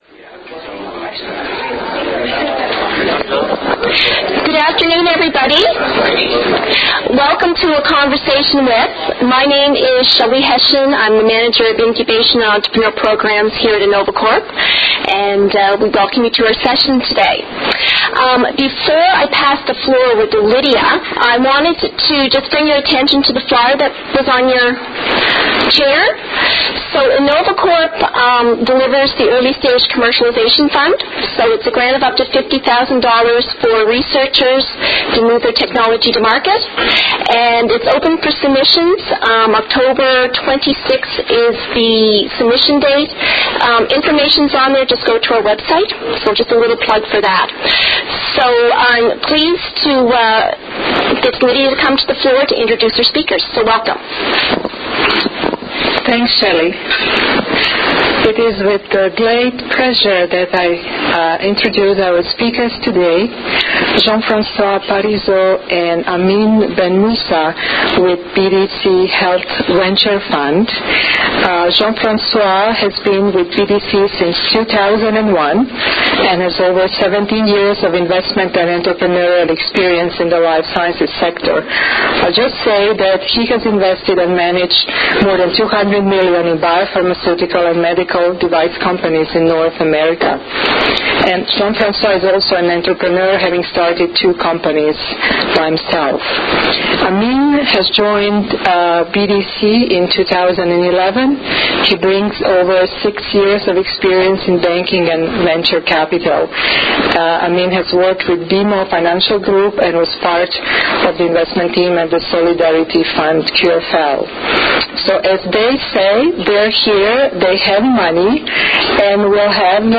CLICK HERE TO LISTEN TO A PODCAST OF THE EVENT BELOW THAT HAPPENED ON SEPTEMBER 26, 2012, AT THE INNOVACORP ENTERPRISE CENTRE.